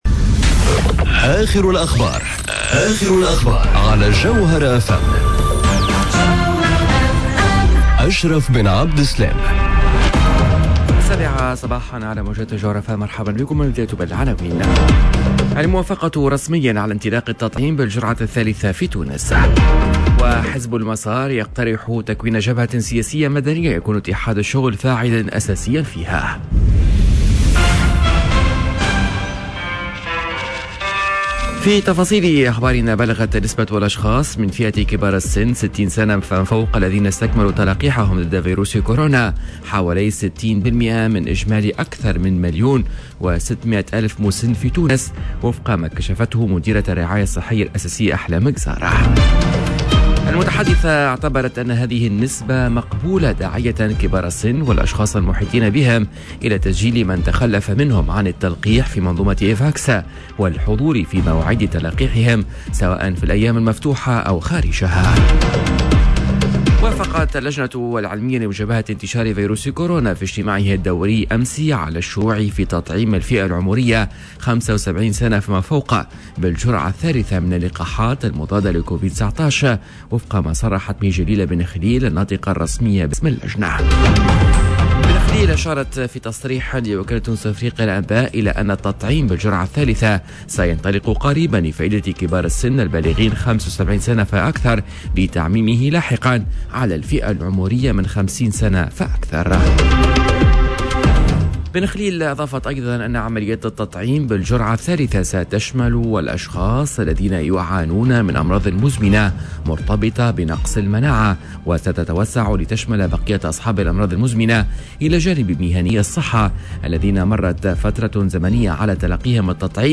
نشرة أخبار السابعة صباحا ليوم الإربعاء 29 سبتمر 2021